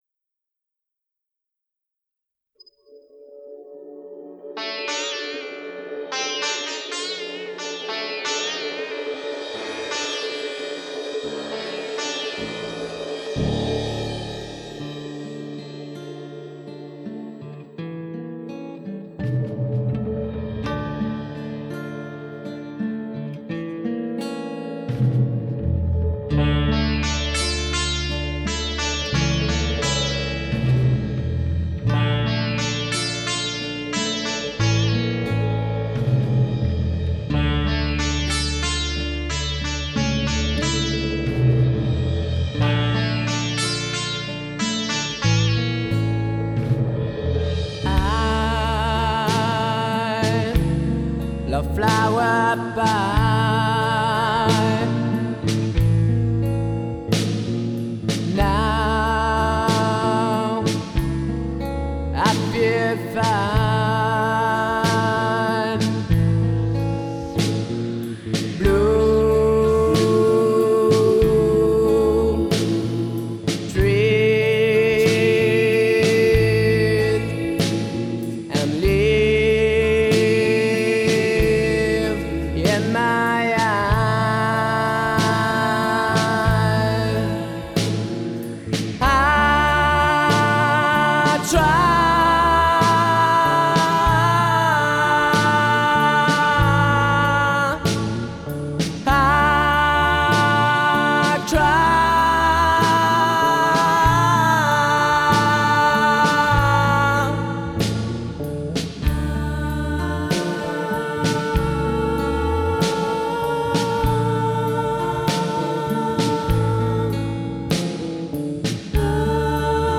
enregistrements au Studio Arema, Bordeaux
basse & chant
batterie & percussions
chant & guitare
claviers